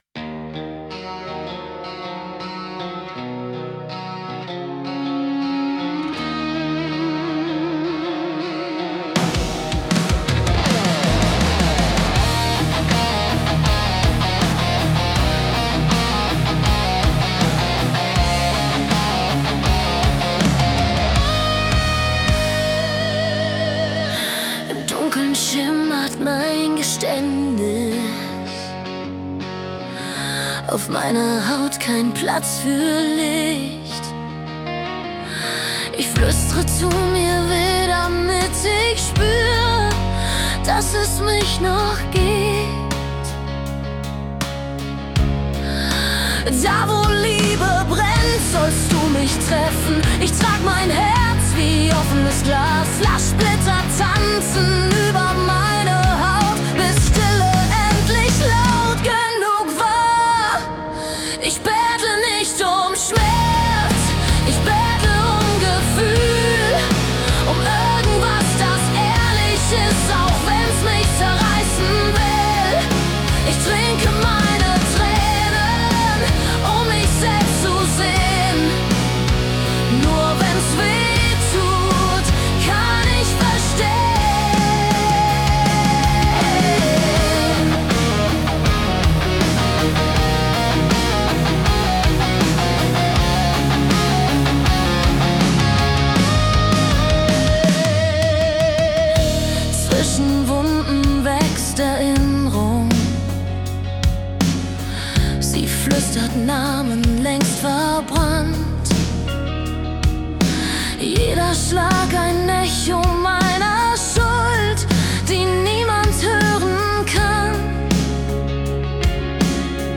ist ein intensiver, düsterer Metal-Song
Genre: Metal